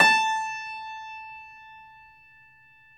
53d-pno17-A3.wav